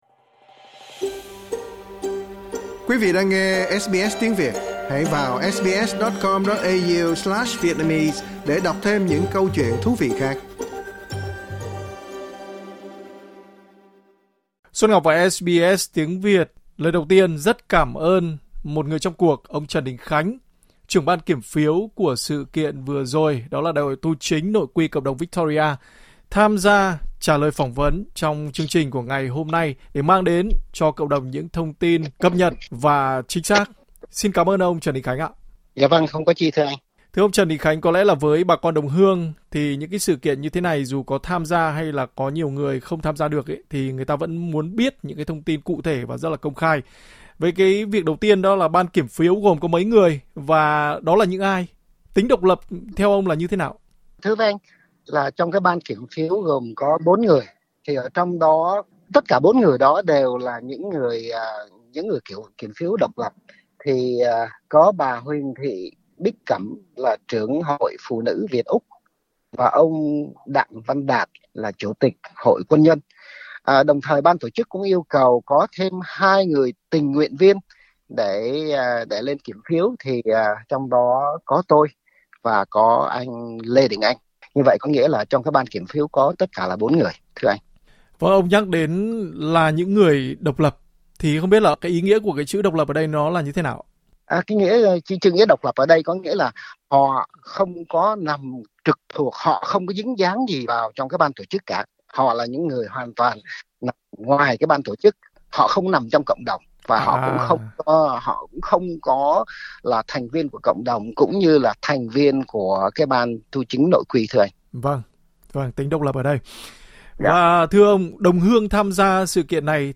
Trả lời phỏng vấn SBS Tiếng Việt